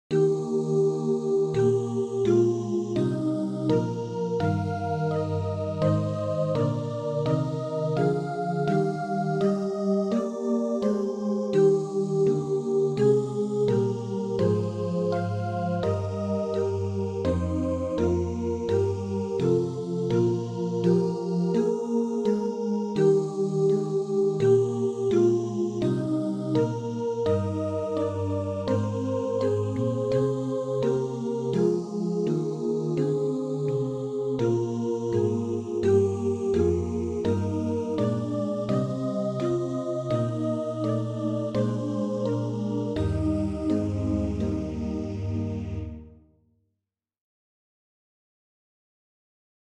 This is a choir plus congregation arrangement.
Voicing/Instrumentation: SATB